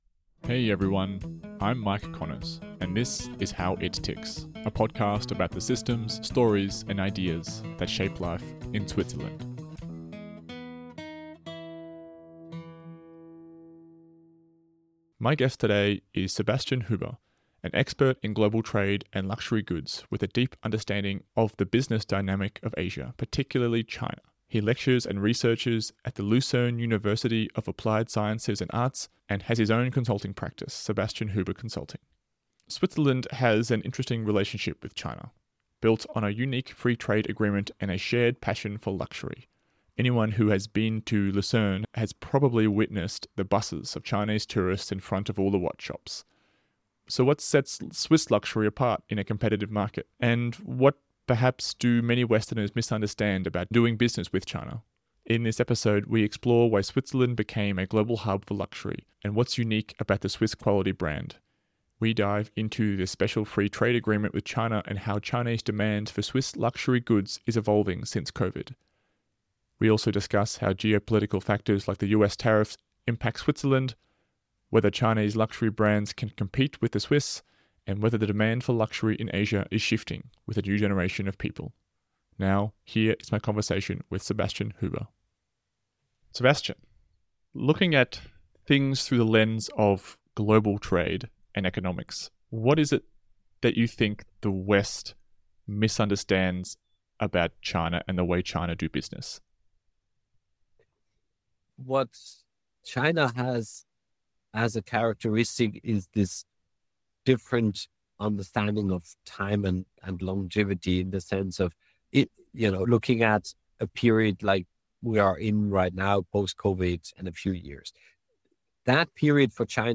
The conversation also explores how global political factors impact Switzerland and the future of luxury consumption in Asia.